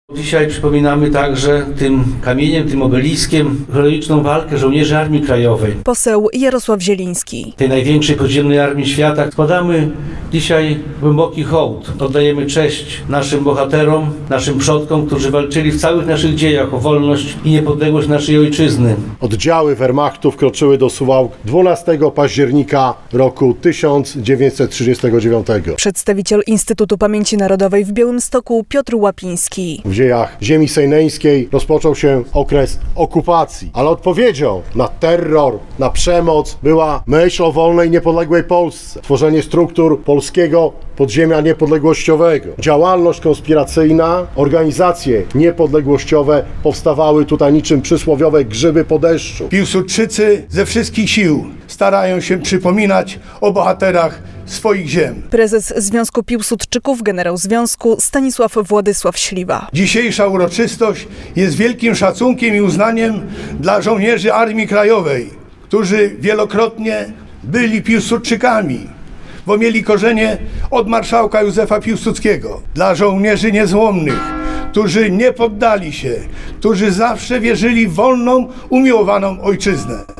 Następnie uczestnicy przenieśli się pod pomnik, gdzie wygłoszono okolicznościowe przemowy.
relacja